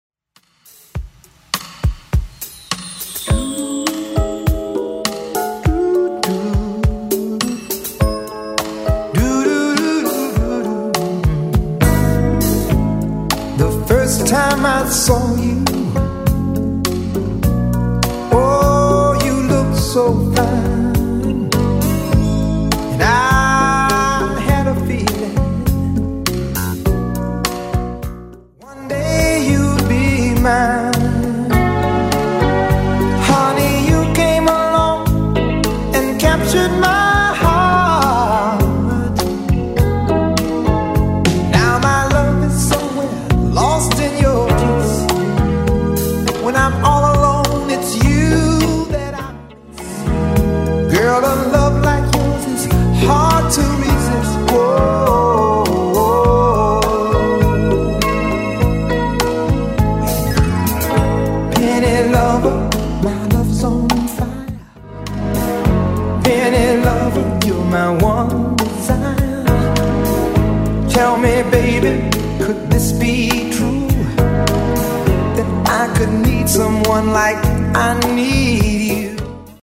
Genre: 80's Version: Clean BPM: 124